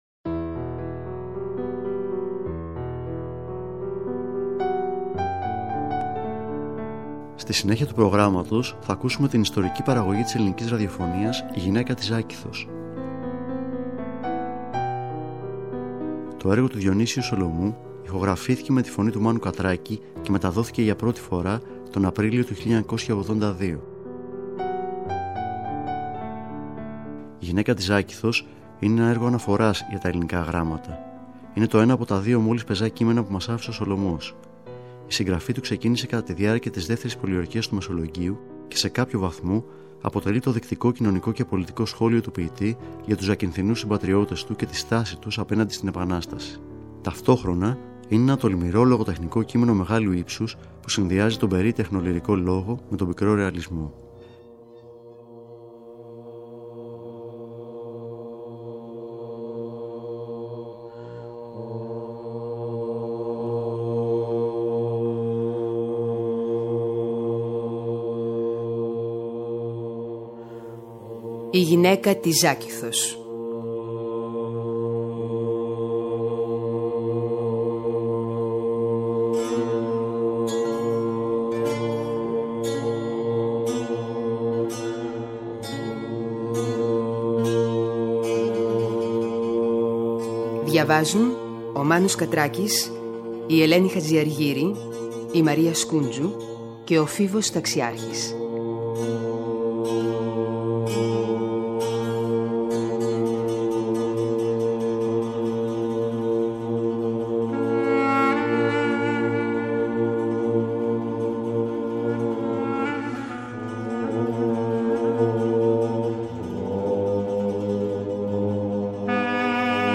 Μνημειακό χαρακτήρα έχει πλέον και η ηχογράφηση του έργου με τη φωνή του Μάνου Κατράκη, που μεταδόθηκε για πρώτη φορά από την ελληνική ραδιοφωνία τον Απρίλιο του 1982. Η υπερβατική αίσθηση που δημιούργησε η έξοχη απαγγελία του, συνδυασμένη με τις κατανυκτικές ερμηνείες των μελων Ελληνικής Βυζαντινής Χορωδίας, θα αναβιώσει φέτος τη Μεγάλη Παρασκευή 03 Μαΐου 2024, από τη συχνότητα του Δεύτερου Προγράμματος.